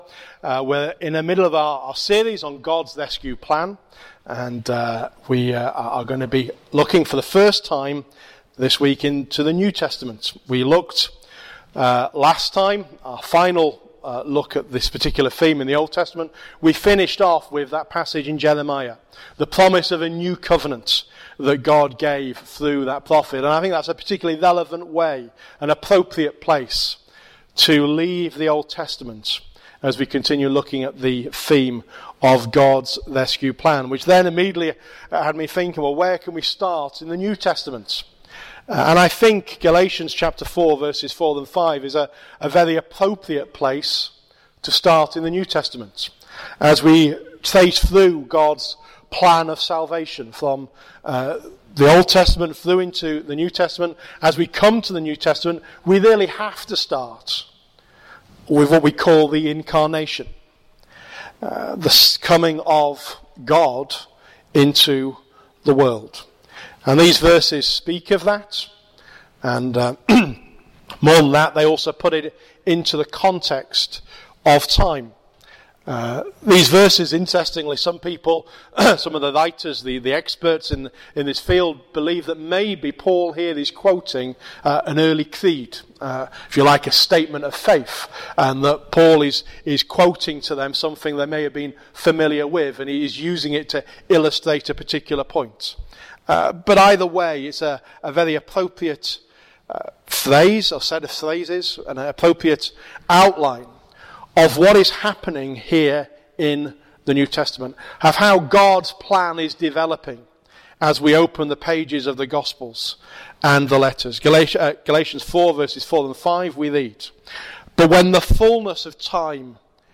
Sermon Recording and Outline on Galatians Chapter 4 verses 4-5